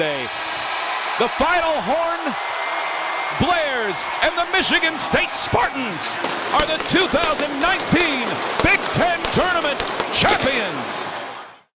B1G TEN Basketball Tournament 2026 Final CALL
BIGTEN2019Spartansat_the__buzzer.wav